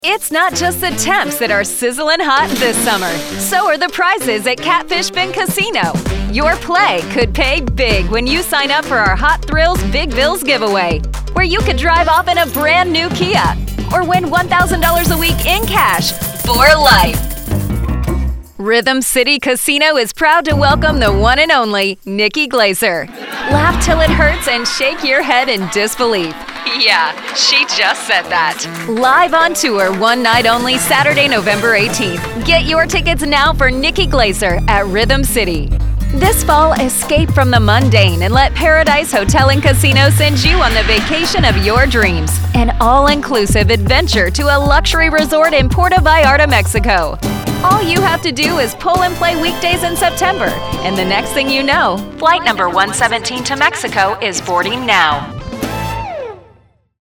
Female
My voice is youthful, upbeat, conversational and relatable.
Video Games
Gaming Demo
Words that describe my voice are Engaging, Conversational, Relatable.